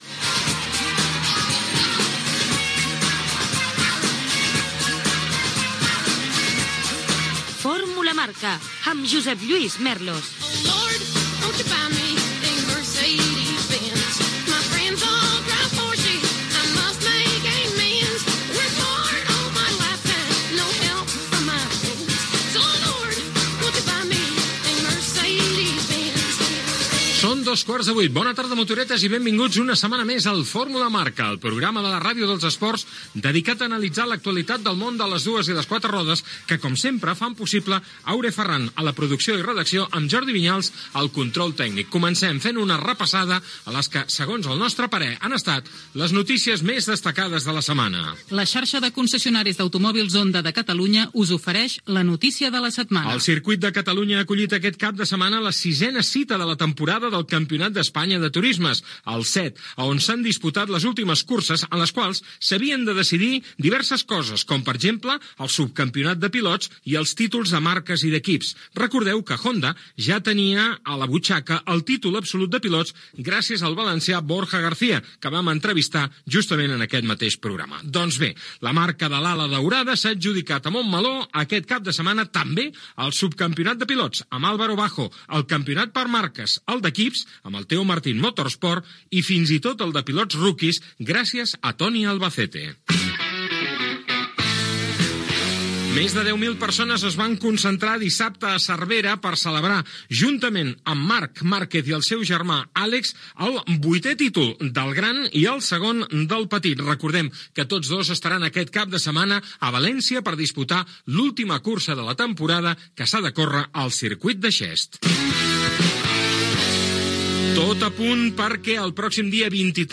Careta del programa, hora, equip, repàs a l'actualitat esportiva del motor, publicitat, secció "Banc de proves" dedicada a Alpine
Esportiu
FM